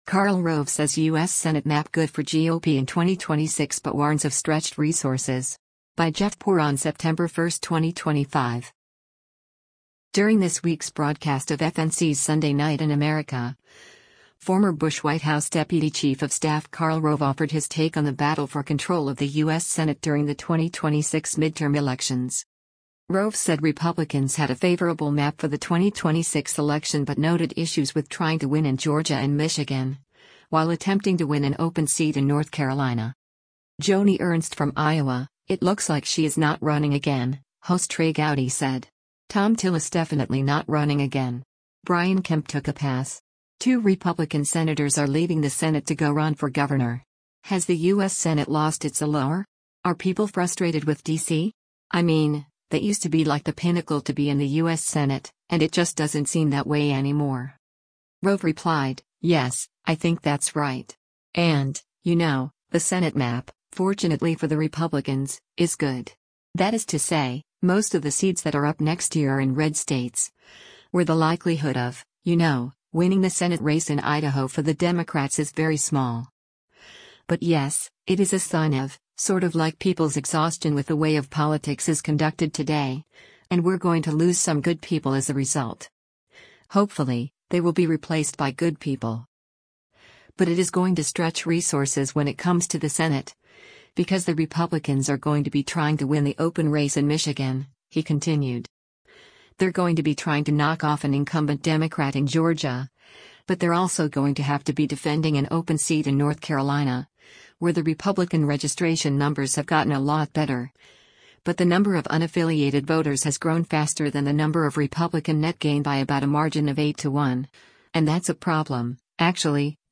During this week’s broadcast of FNC’s “Sunday Night in America,” former Bush White House deputy chief of staff Karl Rove offered his take on the battle for control of the U.S. Senate during the 2026 midterm elections.